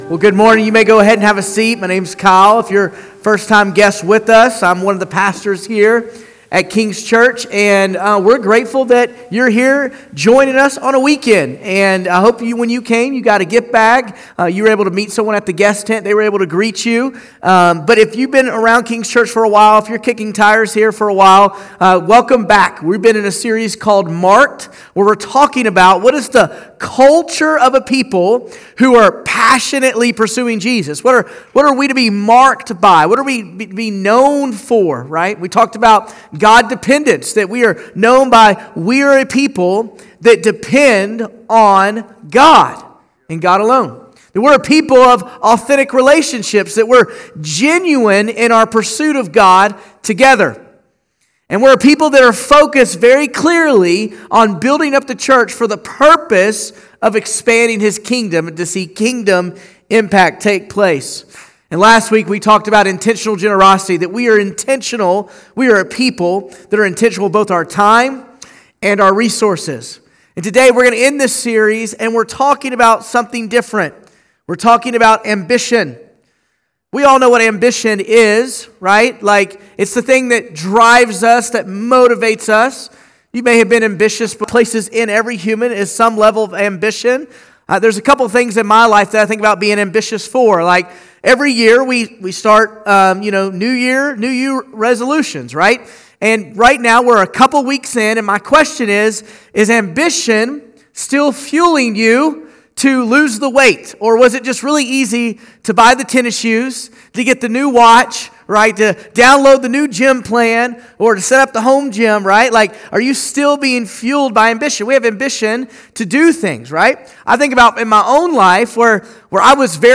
February1sermon.mp3